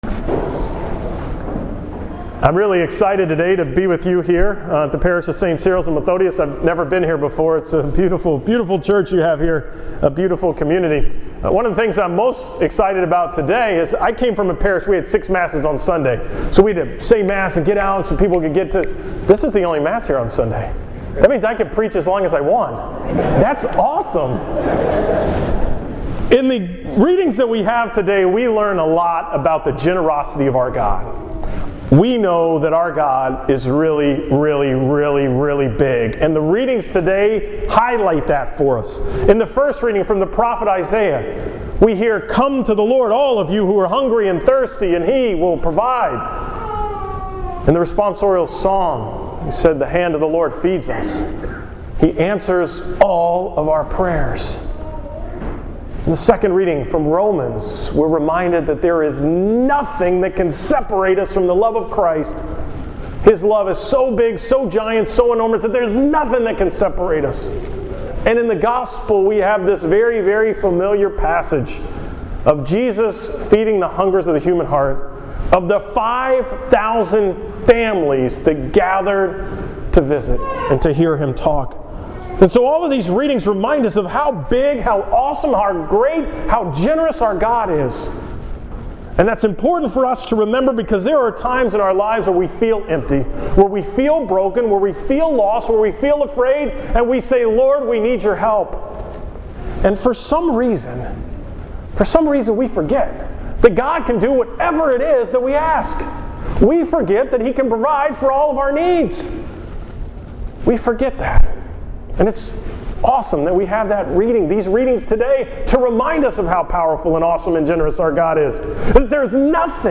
From the 8:30 am Mass at Sts. Cyril and Methodius in Damon, TX
Category: 2014 Homilies